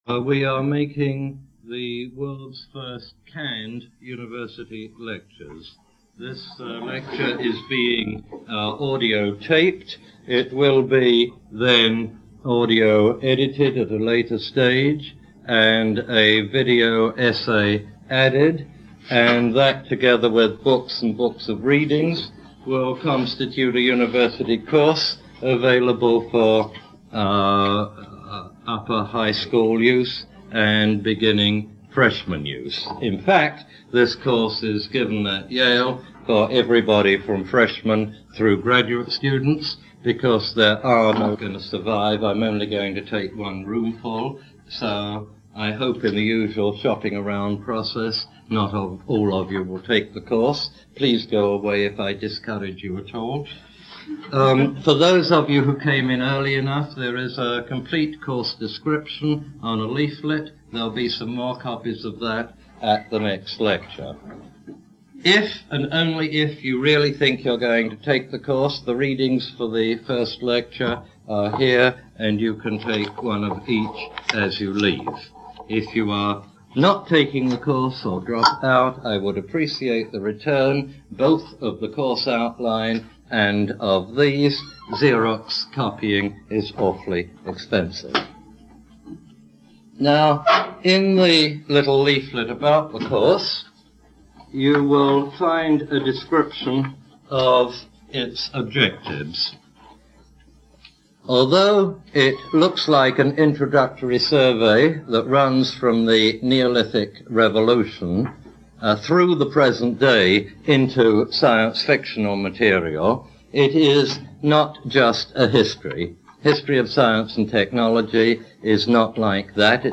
Derek de Solla Price "Neolithic to Now" Lecture #1, Yale 1976.